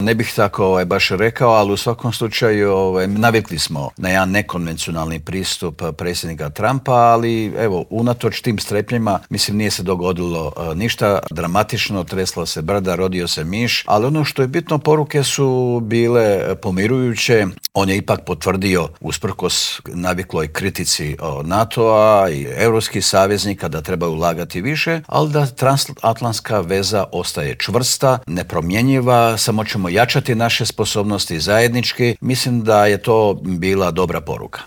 ZAGREB - U Intervjuu tjedna Media servisa gostovao je ministar vanjskih i europskih poslova Gordan Grlić Radman s kojim smo proanalizirali poruke poslane iz Davosa i Bruxellesa, kako od američkog predsjednika Donalda Trumpa i ukrajinskog predsjednika Volodimira Zelenskog tako i od premijera Andreja Plenkovića.